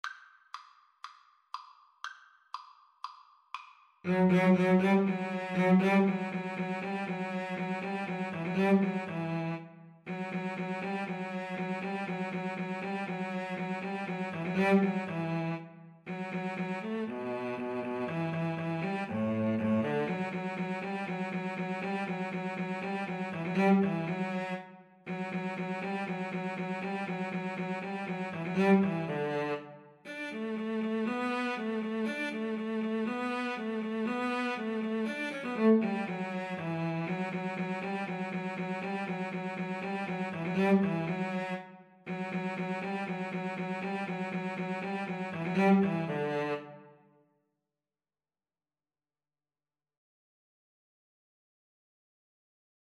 4/4 (View more 4/4 Music)
Allegro (View more music marked Allegro)
Guitar-Cello Duet  (View more Easy Guitar-Cello Duet Music)
Classical (View more Classical Guitar-Cello Duet Music)